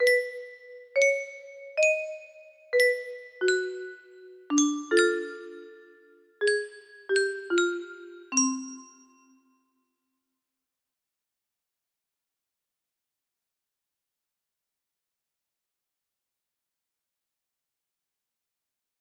Ugh songs are hard music box melody